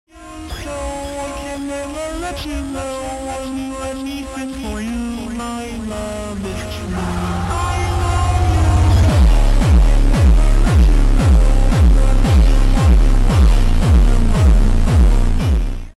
JUMPSTYLE (Super Slowed & Bassboosted)